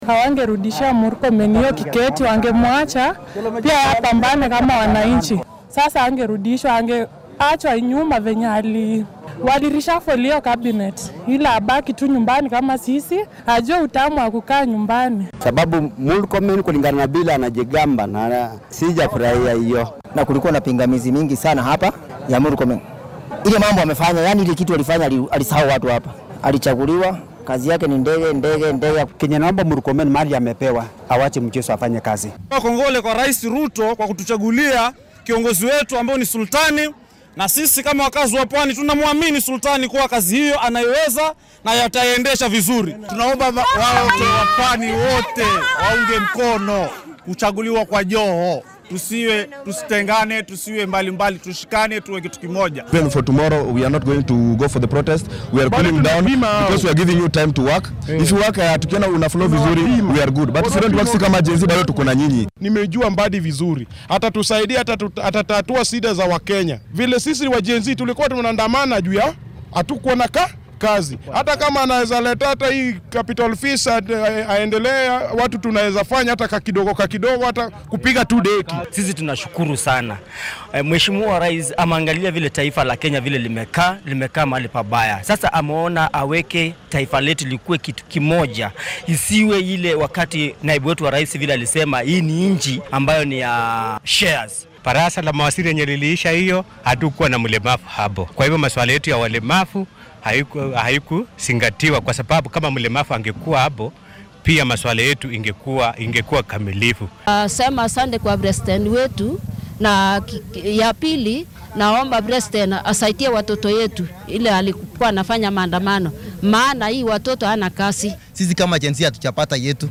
DHAGEYSO:Shacabka oo muujiyay dareenkooda ku aaddan wasiirrada la soo magacaabay